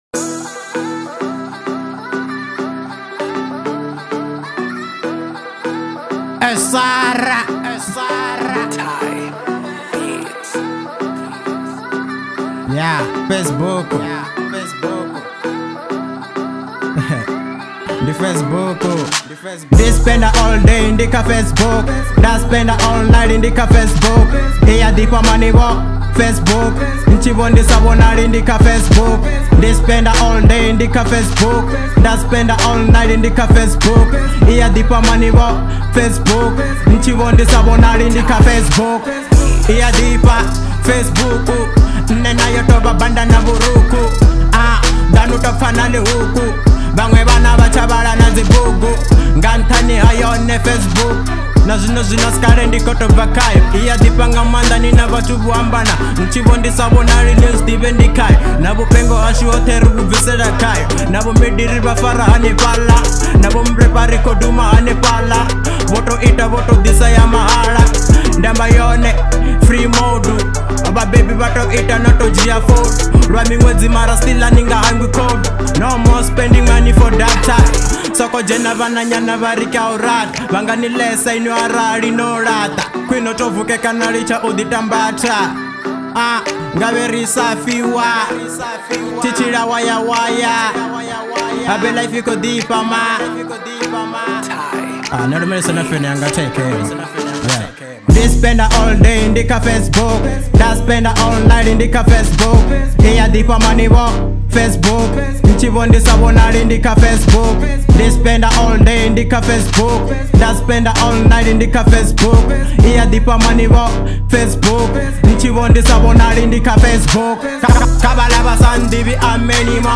04:16 Genre : Hip Hop Size